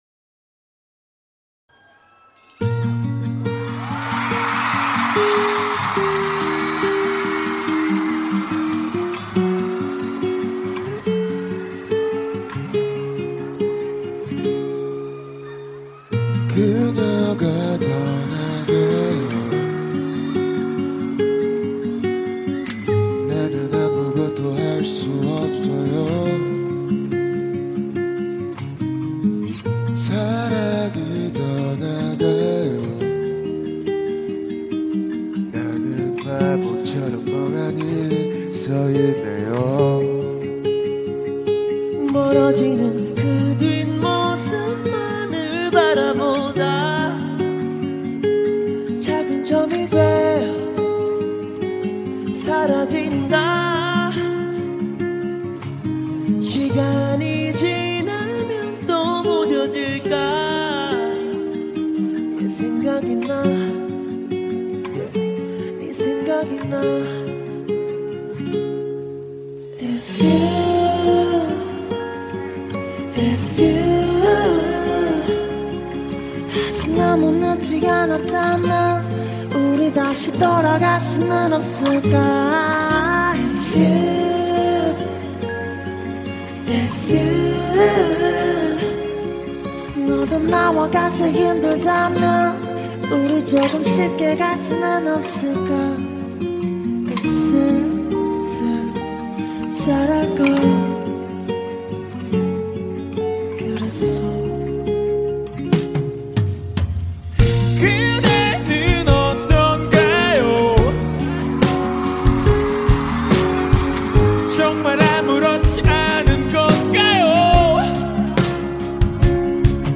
result : Live performance even better than studio version